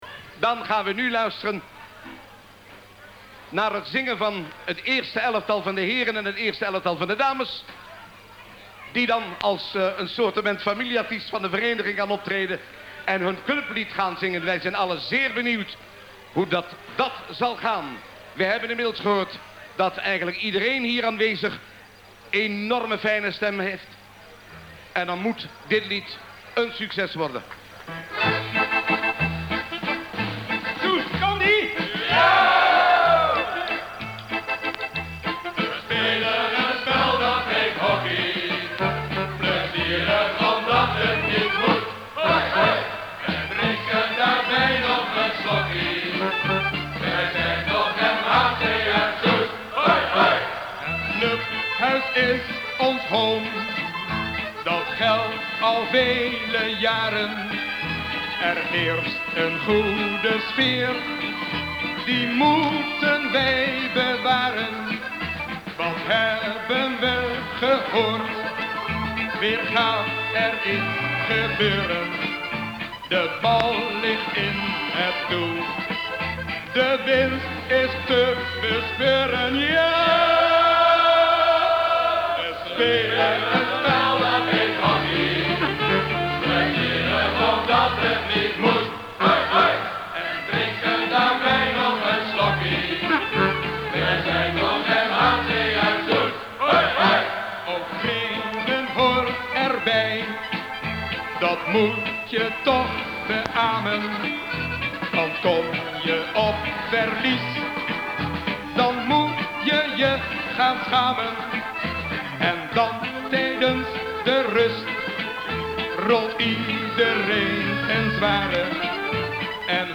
In de bijlage vind je de originele opname van dit lied.
LIEDmetaankondigingMP3.mp3